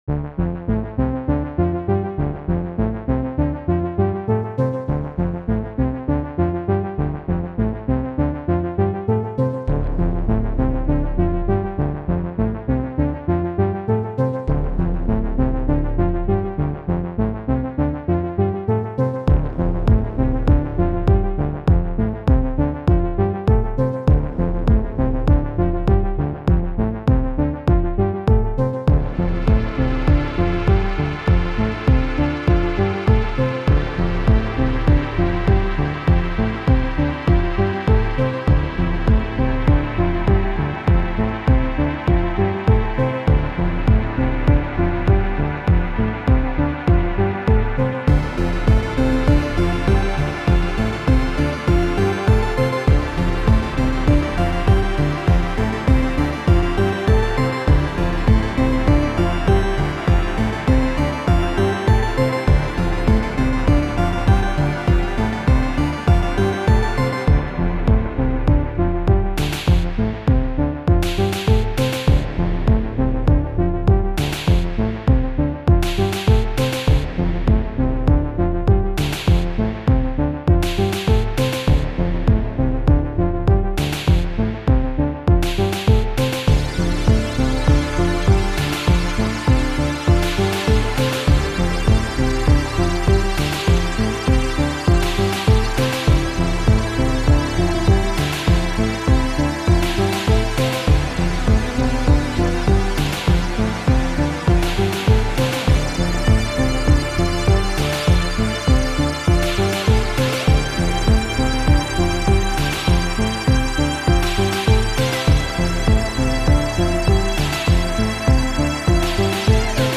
Unexplained schoolday adventures recovered. in VHS.      mp3